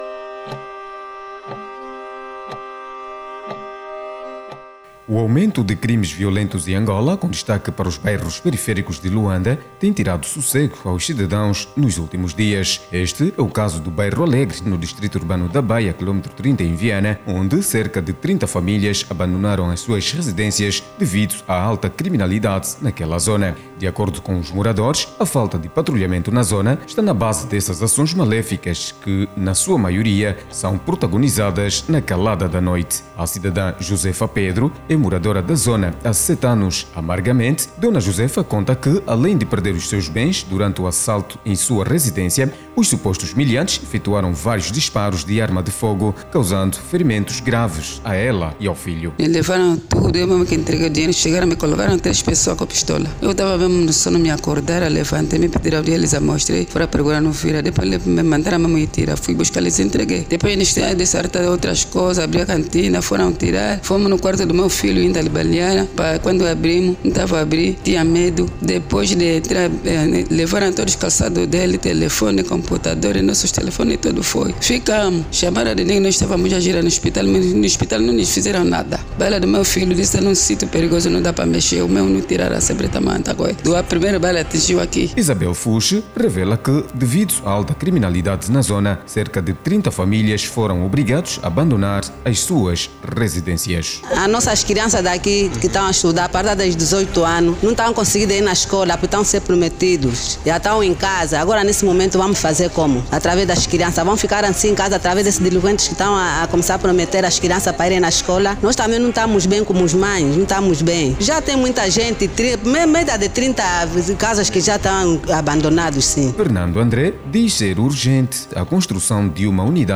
Reportagem completa